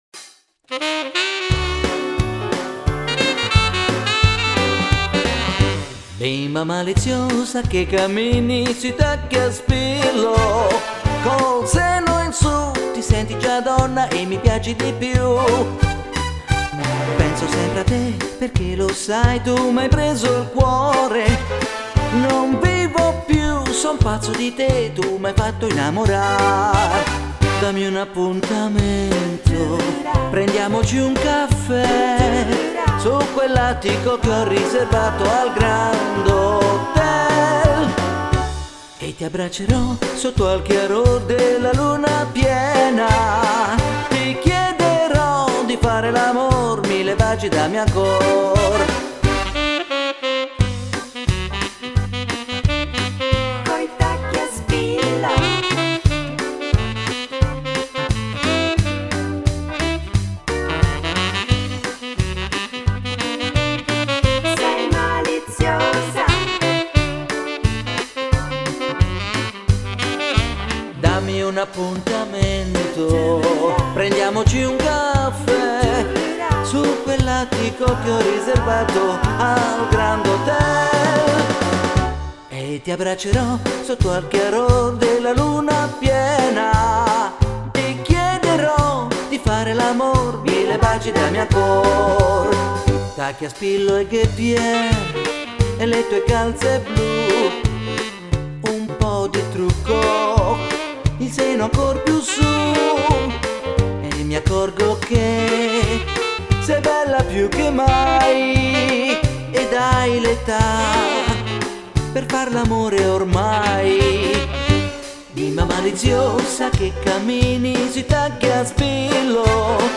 Quick step
Due canzoni orecchiabili